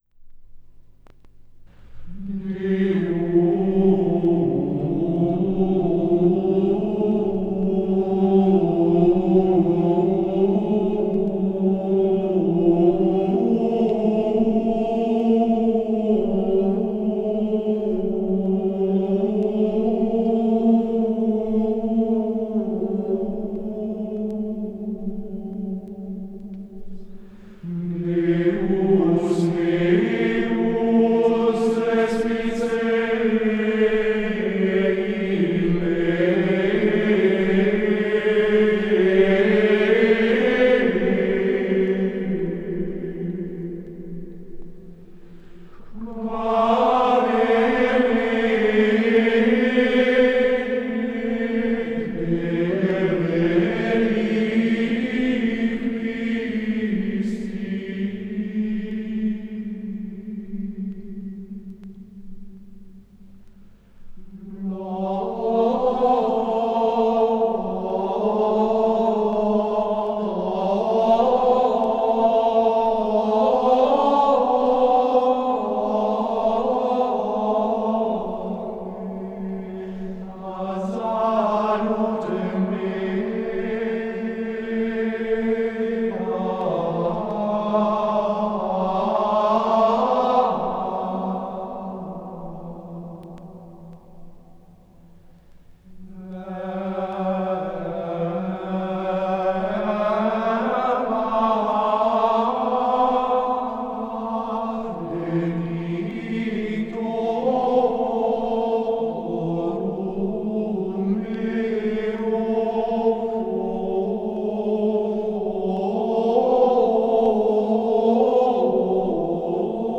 Gregorianischen Chorals
Gesang: Schola gregoriana
aufgenommen in der Klosterkirche Knechtsteden